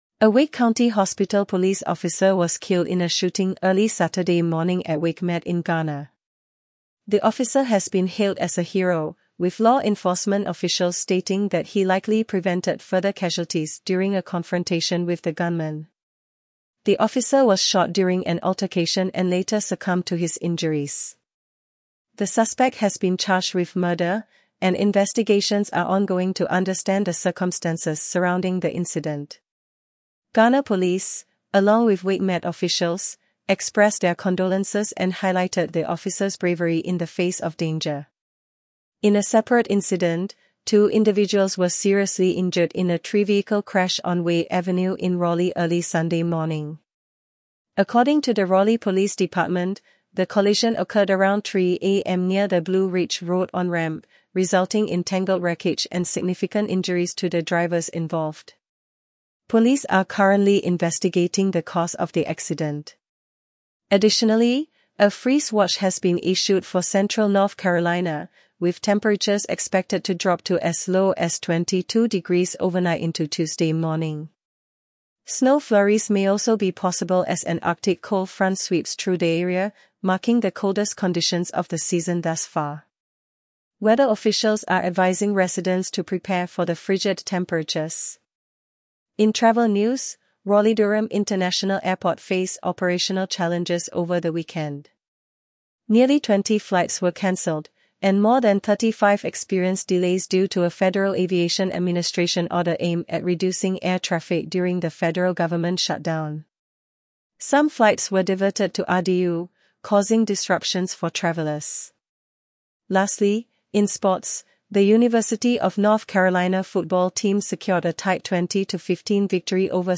Raleigh-Durham News Summary